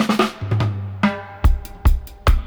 142-FILL-FX.wav